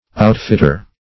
Outfitter \Out"fit`ter\, n.